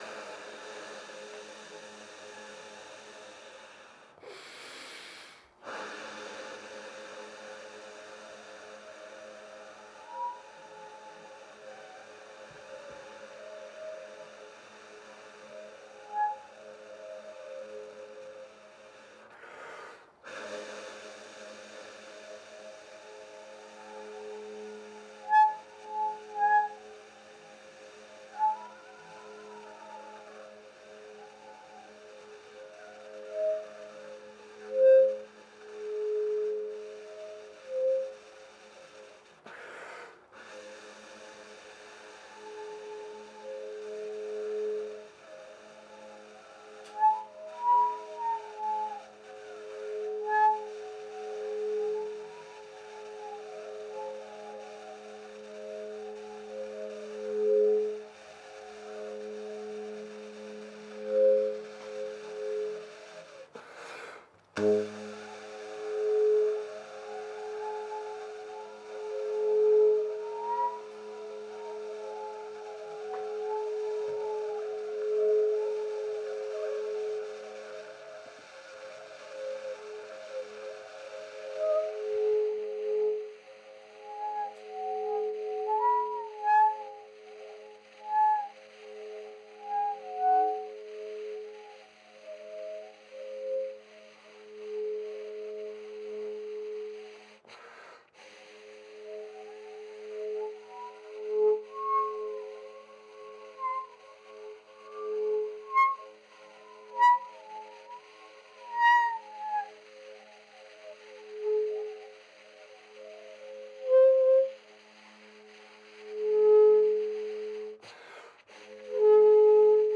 Threads of noise, tape loops, and samples.